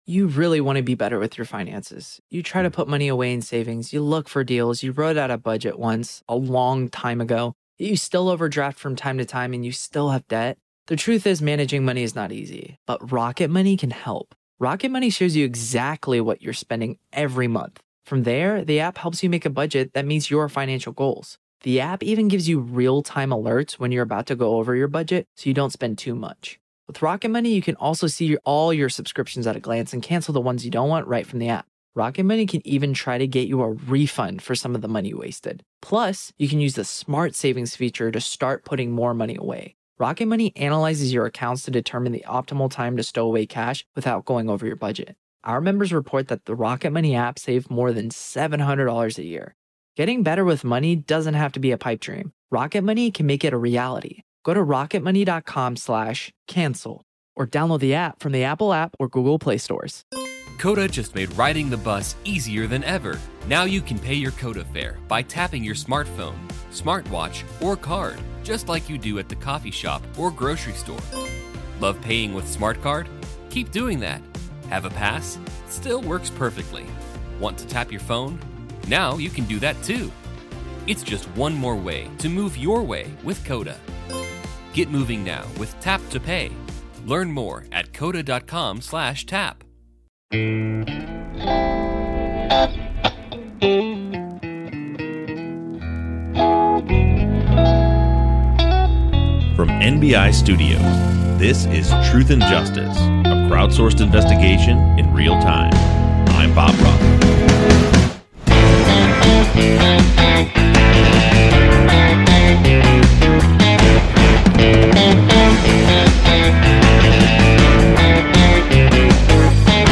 He takes an interesting approach in his closing argument.